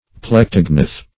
Plectognath \Plec"tog*nath\, a. (Zool.)